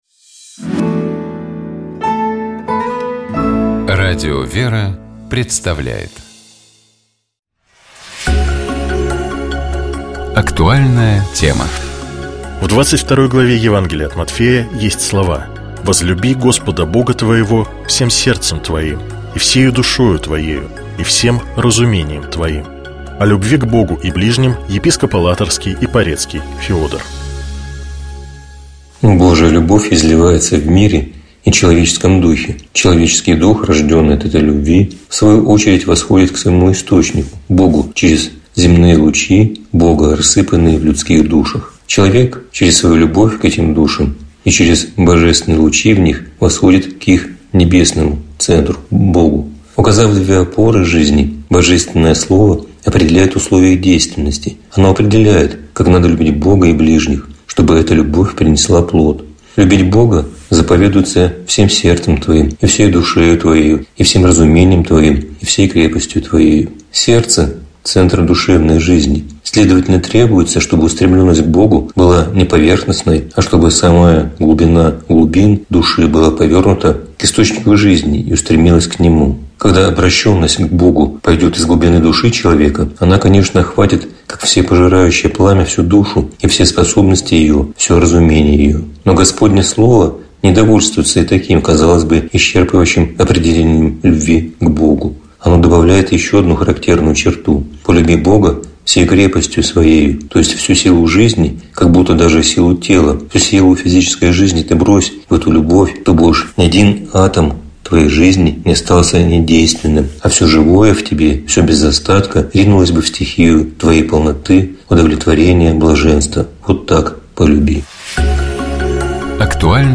О любви к Богу и ближним, — епископ Алатырский и Порецкий Феодор.